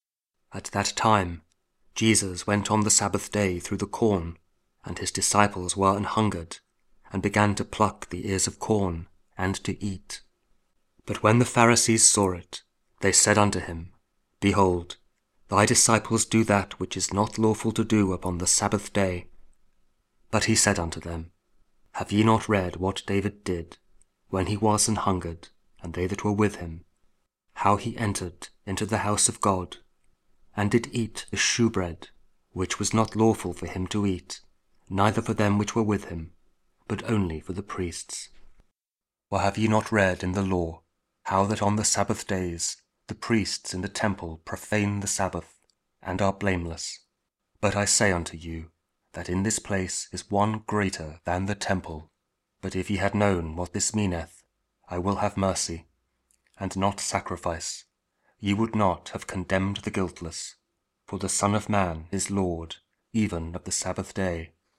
Matthew 12: 1-8 – Week 15 Ordinary Time, Friday (King James Audio Bible KJV, Spoken Word)